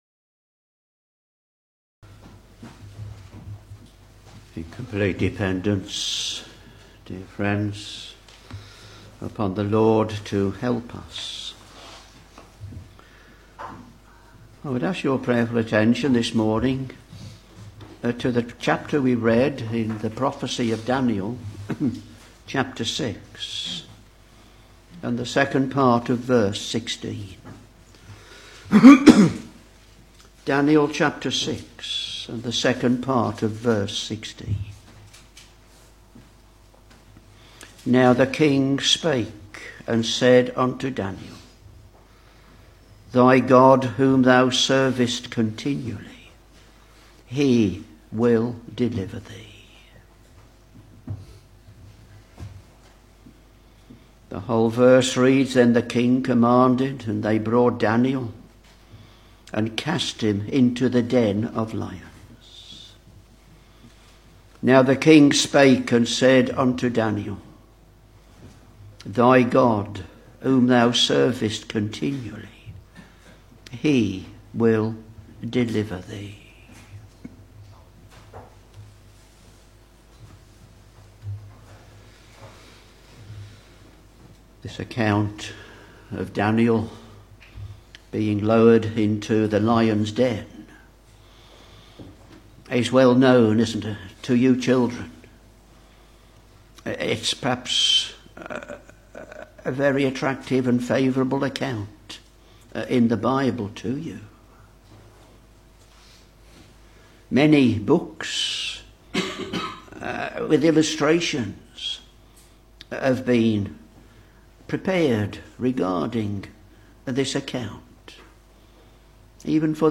Back to Sermons Daniel Ch.6 v.16 (2nd part) Now the king spake and said unto Daniel, Thy God whom thou servest continually, he will deliver thee.